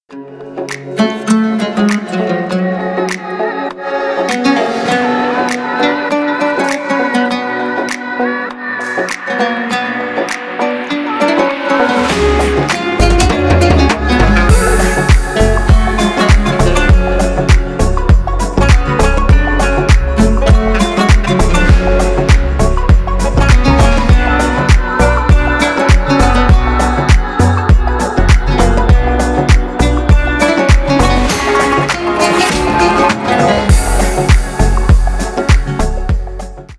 • Качество: 320, Stereo
громкие
deep house
восточные мотивы
Electronic
EDM
Дипхаус с восточными нотками